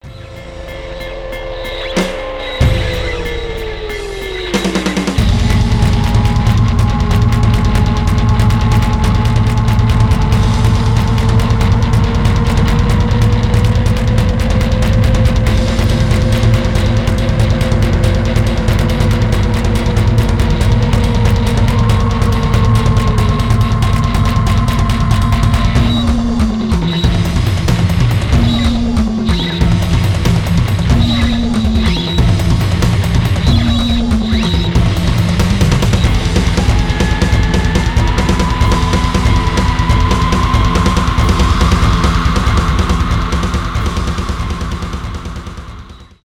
пост-рок
post-metal , experimental , без слов
инструментальные